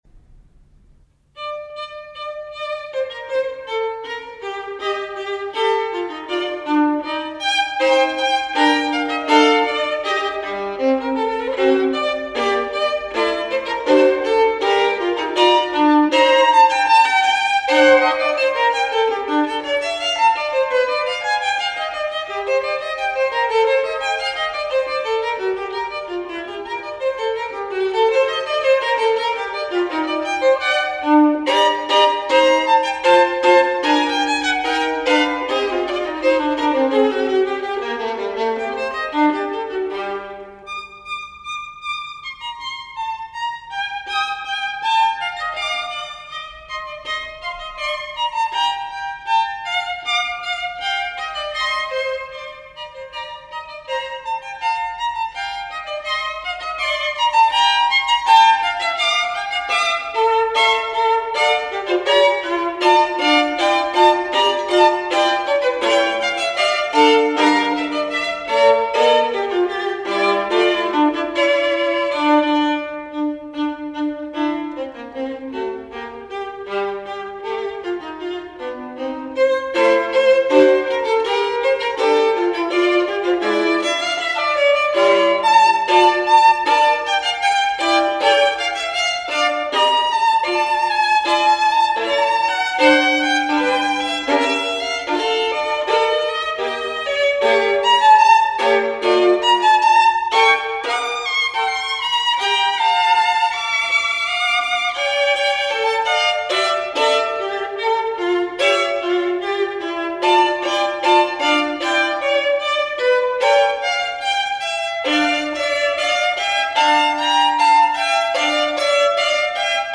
Allegro | Miles Christi